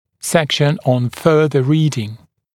[‘sekʃn ɔn ‘fɜːðə ‘riːdɪŋ][‘сэкшн он ‘фё:зэ ‘ри:дин]раздел дополнительной литературы, список дополнительной литературы